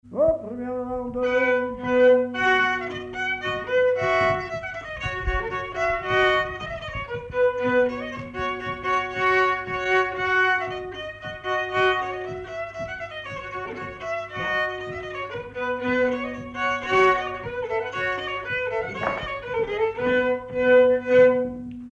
Mémoires et Patrimoines vivants - RaddO est une base de données d'archives iconographiques et sonores.
circonstance : bal, dancerie
Pièce musicale inédite